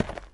gravel1.wav